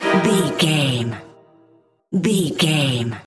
Cello Horror Stab Part 5.
E Minor Cello Horror Stab
Sound Effects
Aeolian/Minor
Fast
ominous
dark
eerie
cello
strings